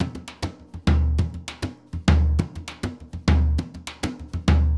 Surdo Baion 100_1.wav